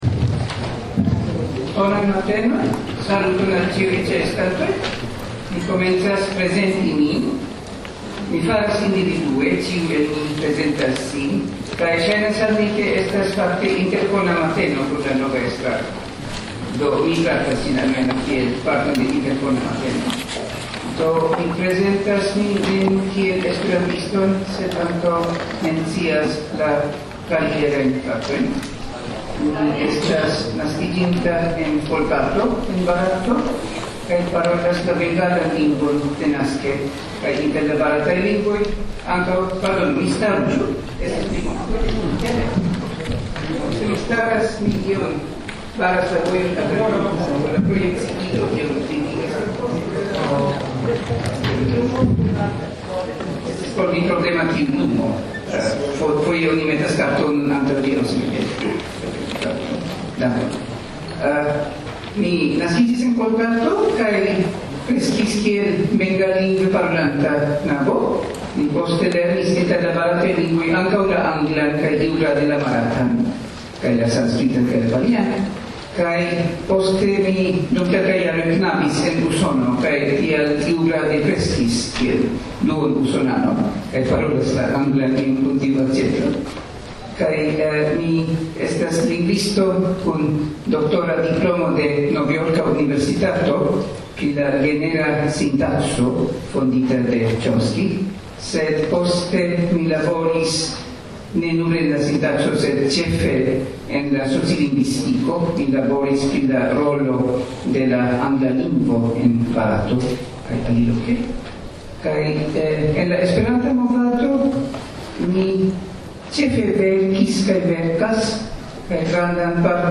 Tricento da kongresanoj ĉeestis ĵaŭde matene, kiam la nova estraro sin prezentis en salono Hodler de la Jokohama Universala Kongreso de Esperanto.
Aŭskultu la sinprezentojn de la estraranoj: [mp3, 22 min]
Komence de la 90-minuta programero ĉiu unuopa estrarano diris kelkajn vortojn pri si mem.